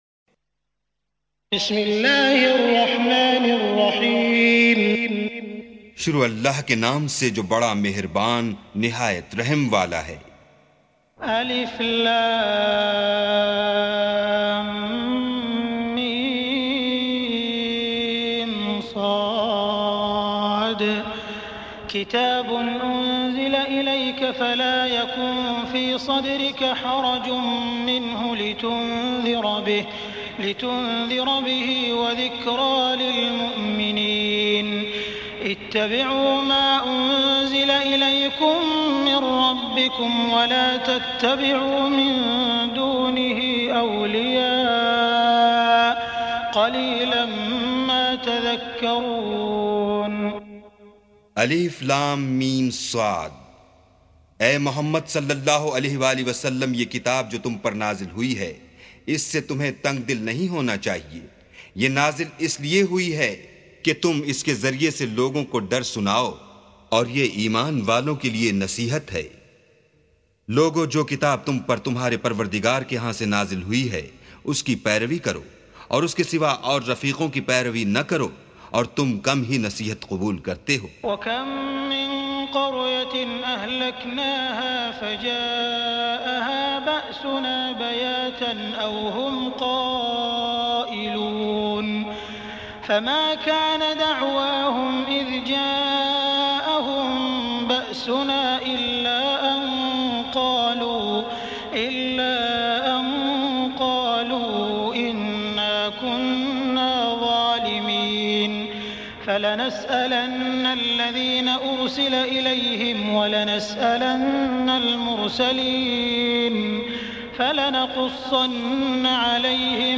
سُورَةُ الأَعۡرَافِ بصوت الشيخ السديس والشريم مترجم إلى الاردو